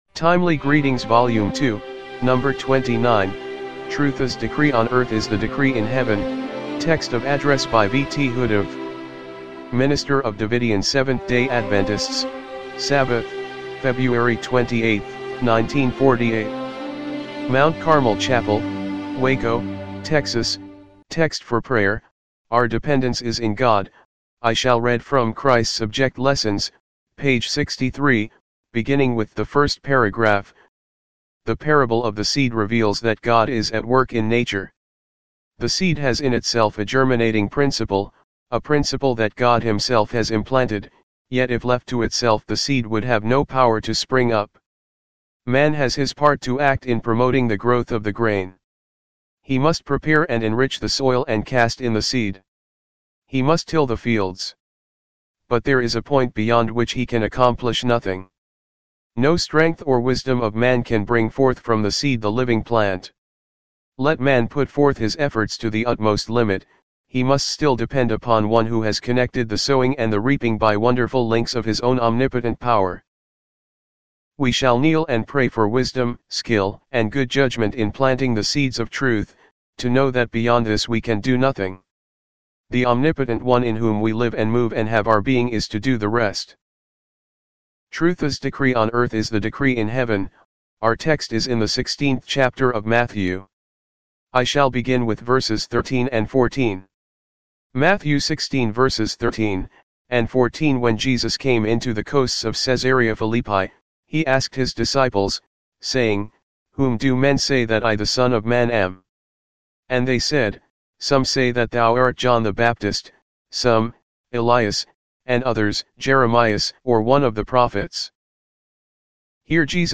timely-greetings-volume-2-no.-29-mono-mp3.mp3